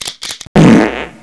FARTGUN.WAV